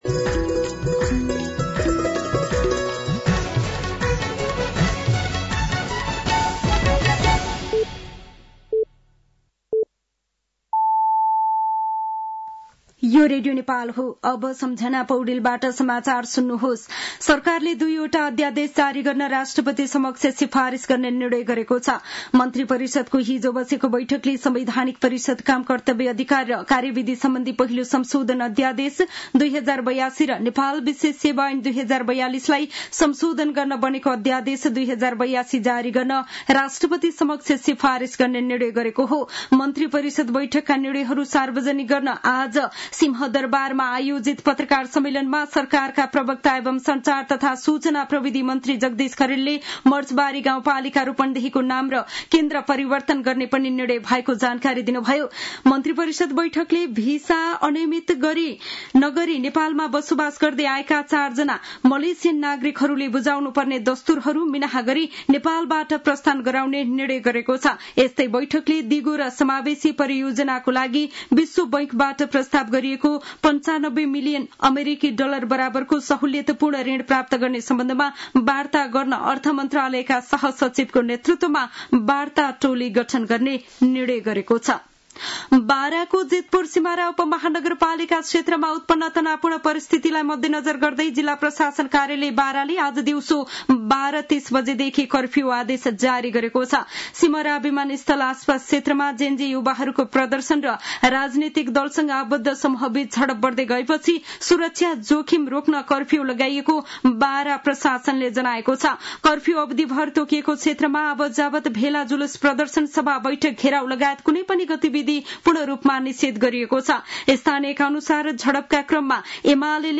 साँझ ५ बजेको नेपाली समाचार : ३ मंसिर , २०८२